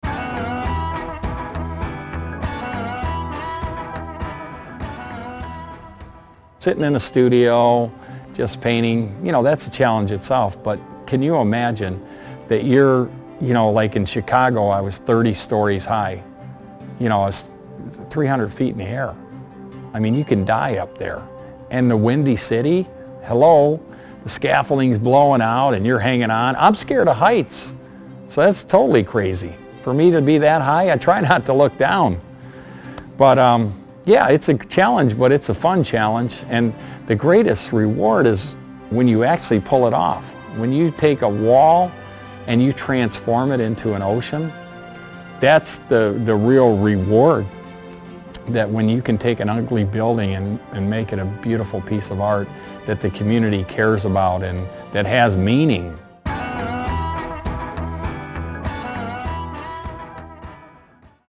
Here is the acclaimed artist’s story in his own words…